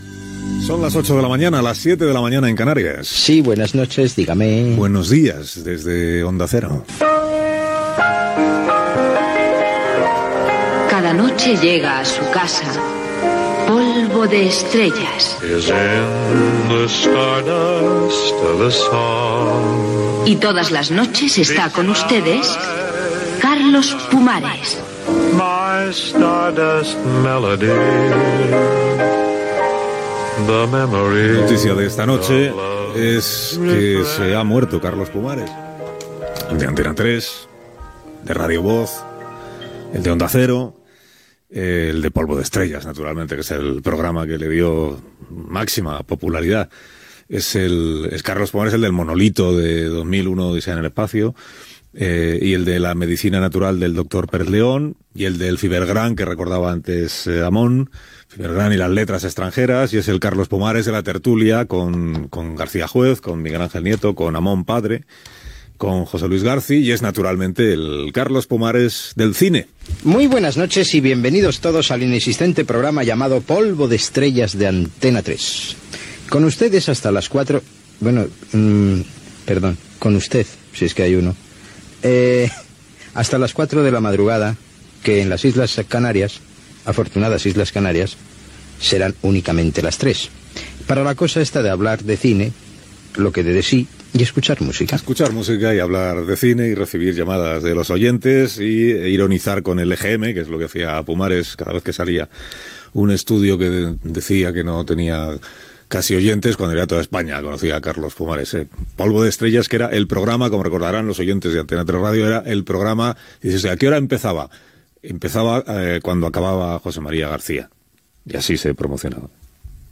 Hora, monòleg del presentador, a les 08:00, sobre la mort de Carlos Pumares
Info-entreteniment
Alsina, Carlos